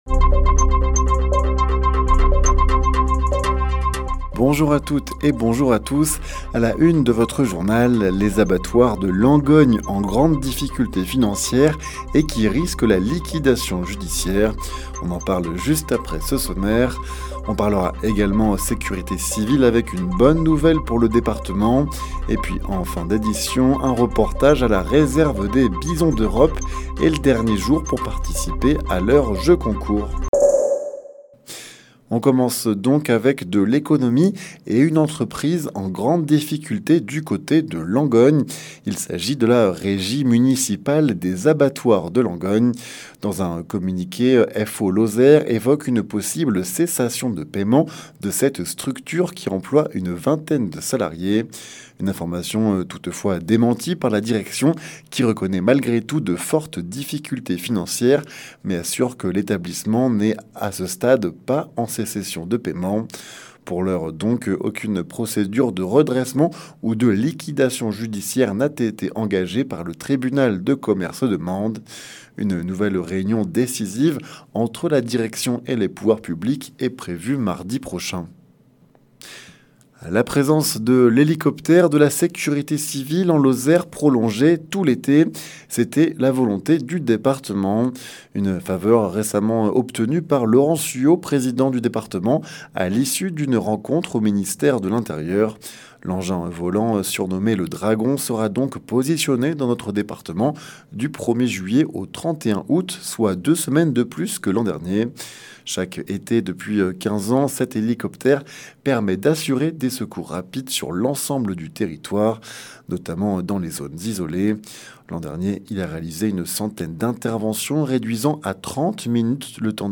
Le journal sur 48FM